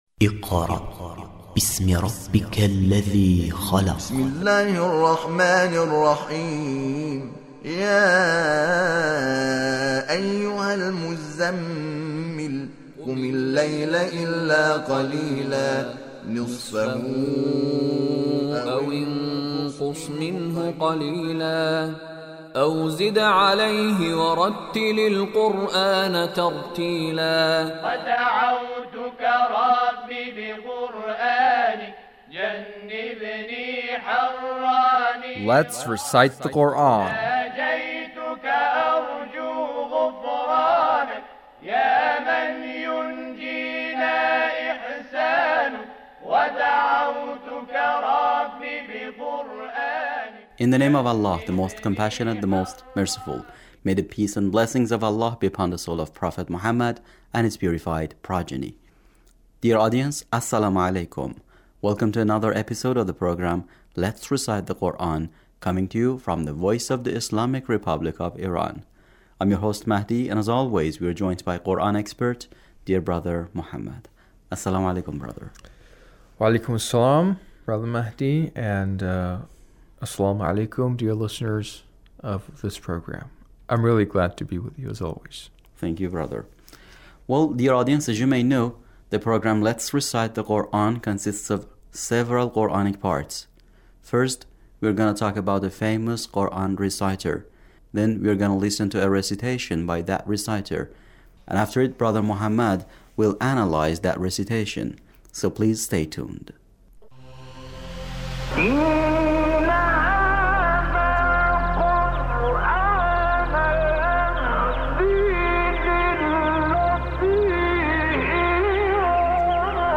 Mostafa Esmaeil recitation